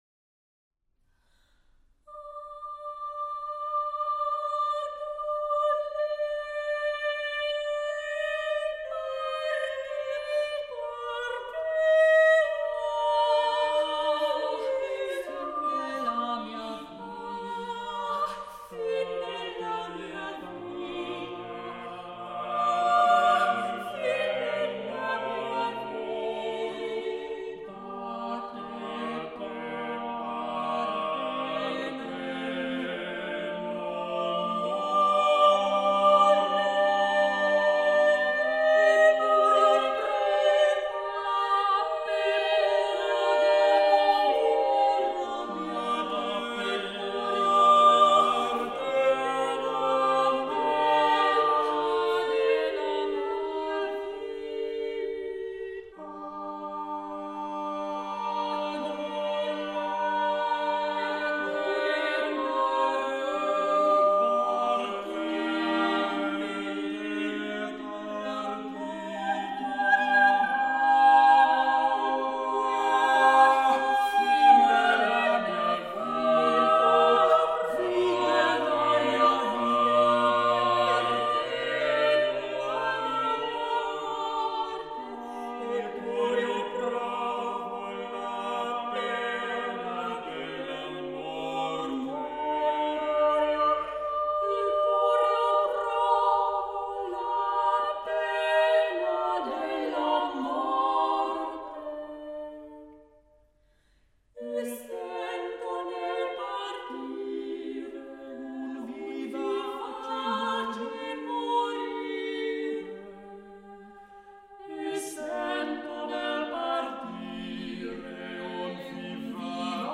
01 - Madrigals, Book 4, for 5 voices, SV 75-93- 1.